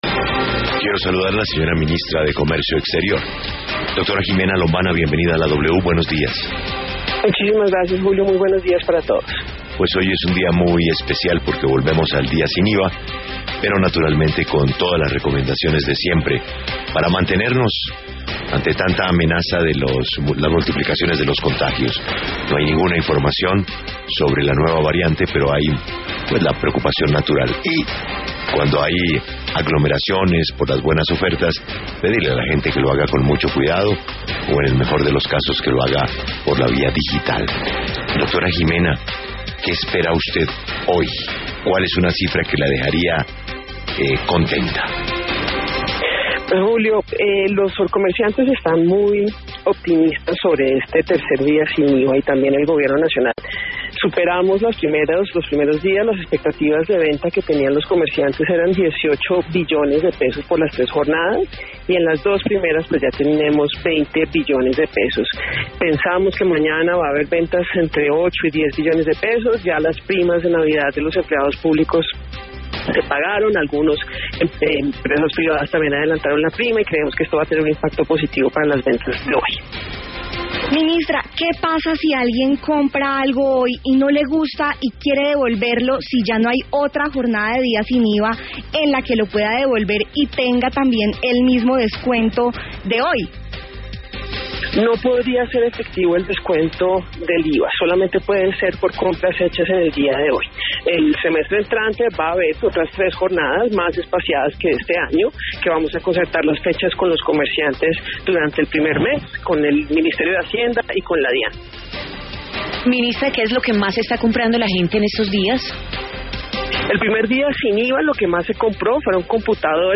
La ministra de Comercio, María Ximena Lombana, aseguró en W Radio que el otro semestre habrá tres jornadas del Día sin IVA.